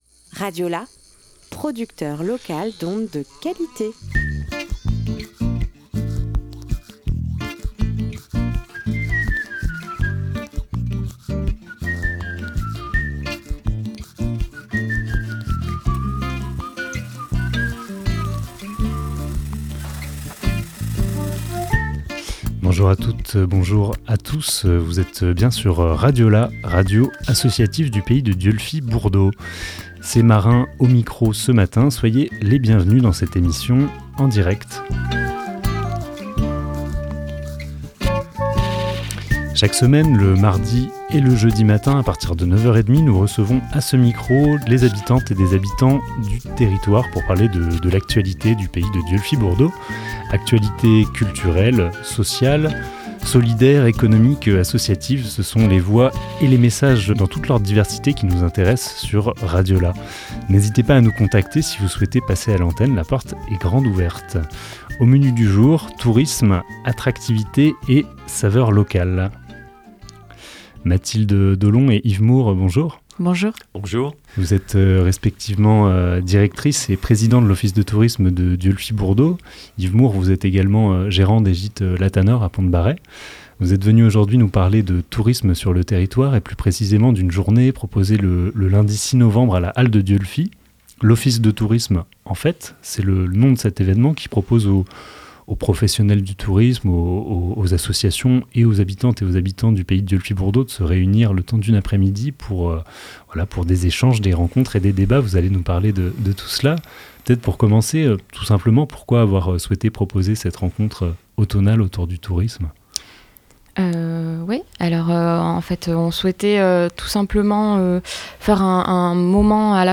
25 octobre 2023 14:36 | Interview